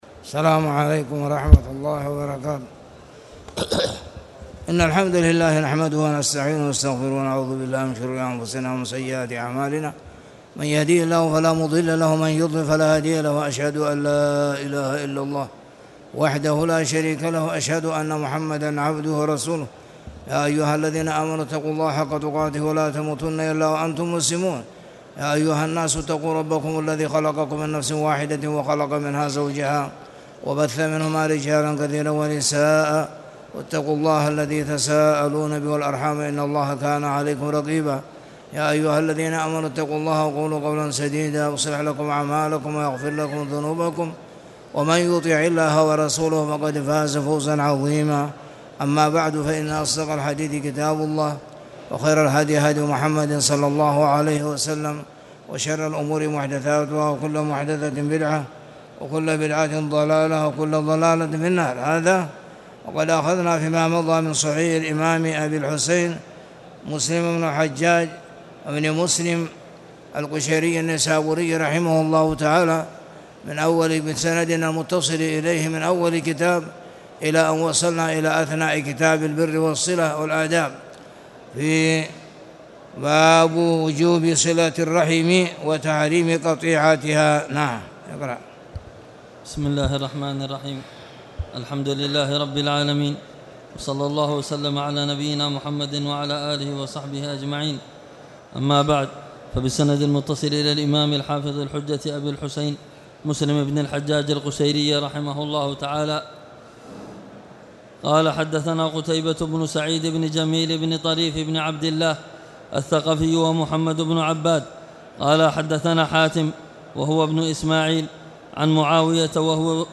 تاريخ النشر ١١ ربيع الثاني ١٤٣٨ هـ المكان: المسجد الحرام الشيخ